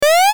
Download Retro Jump sound effect for free.
Retro Jump